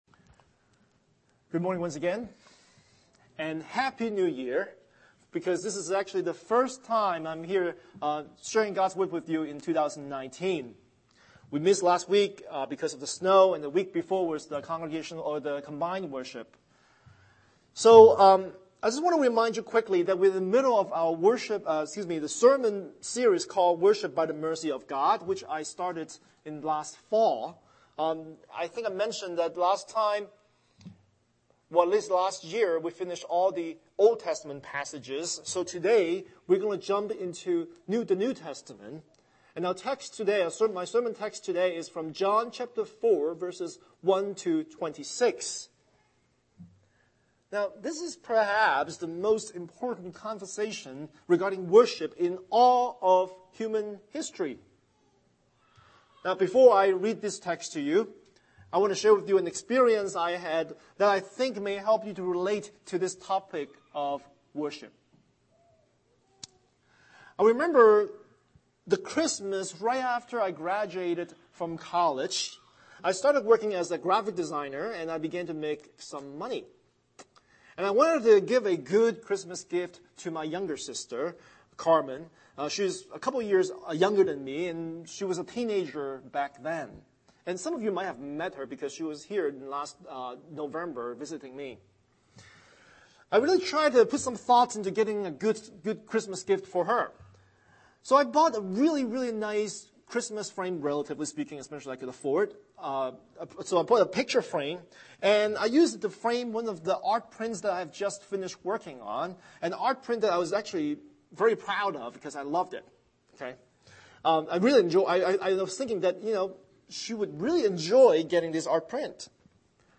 Scripture: John 4:1-26 Series: Sunday Sermon